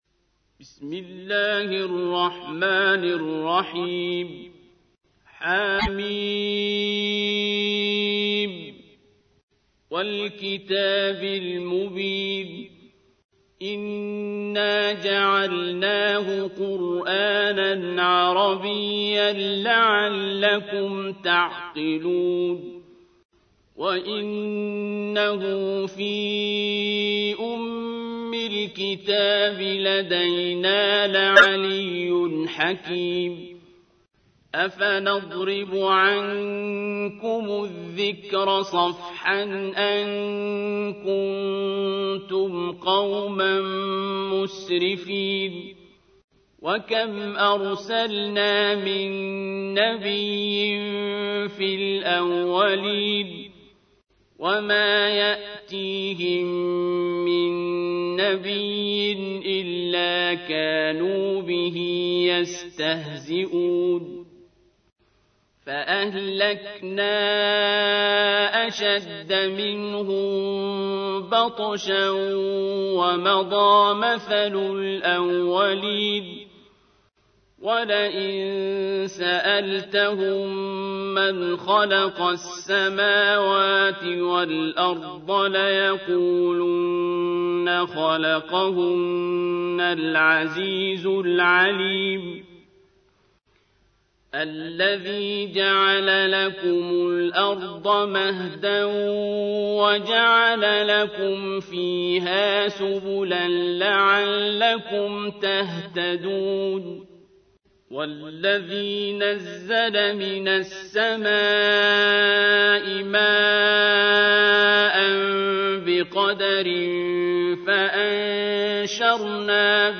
تحميل : 43. سورة الزخرف / القارئ عبد الباسط عبد الصمد / القرآن الكريم / موقع يا حسين